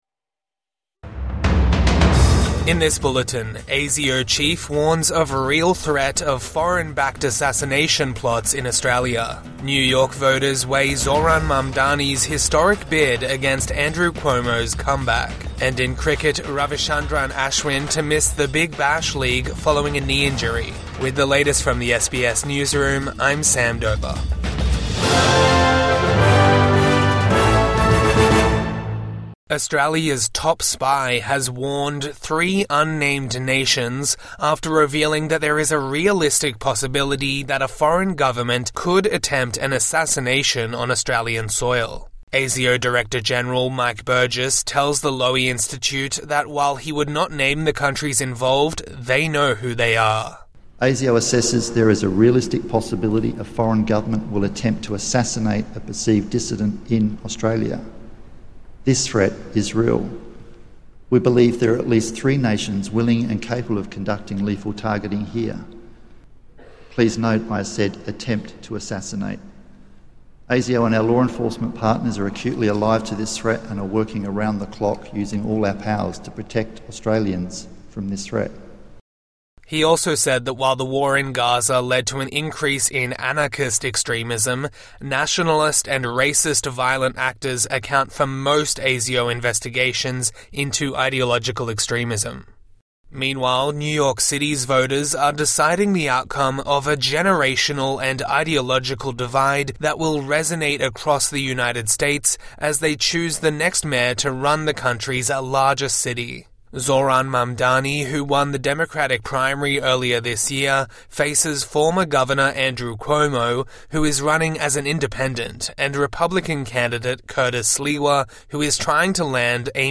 ASIO chief warns of assassination threats from abroad | Morning News Bulletin 5 November 2025